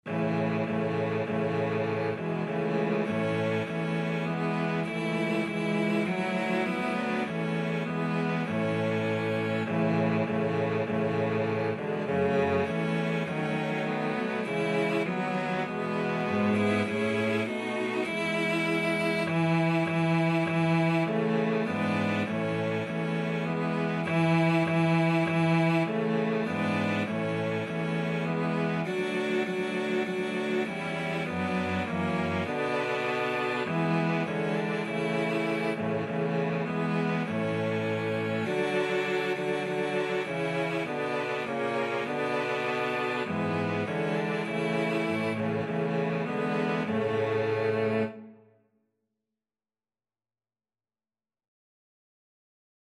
Christmas Christmas Cello Quartet Sheet Music Hark!
A major (Sounding Pitch) (View more A major Music for Cello Quartet )
4/4 (View more 4/4 Music)
Cello Quartet  (View more Intermediate Cello Quartet Music)